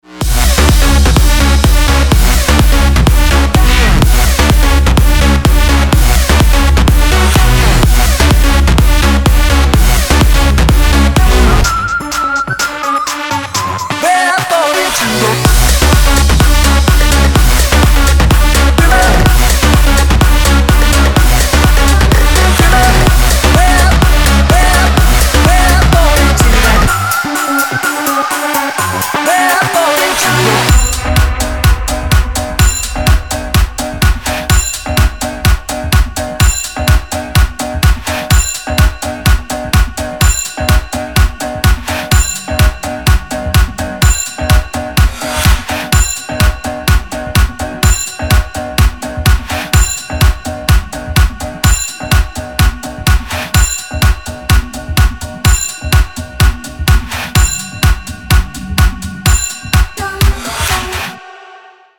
• Качество: 256, Stereo
Dark house